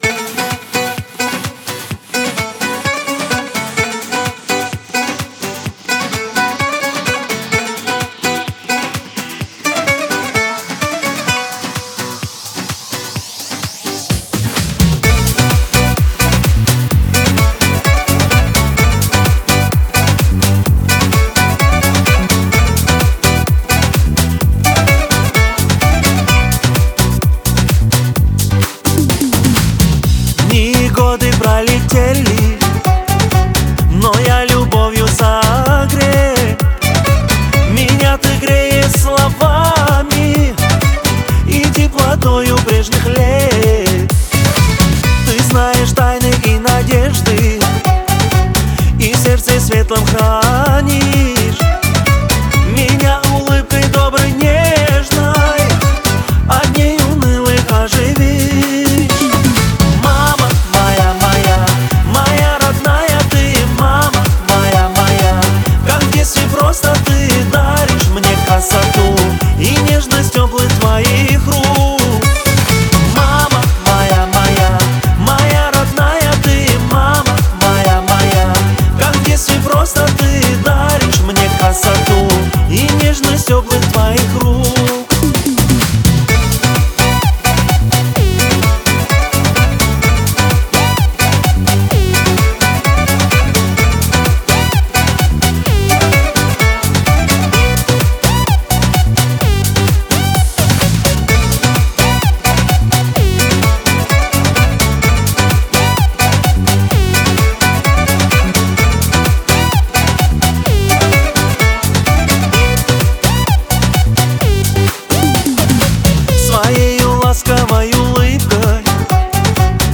Категории: Русские песни, Музыка мира.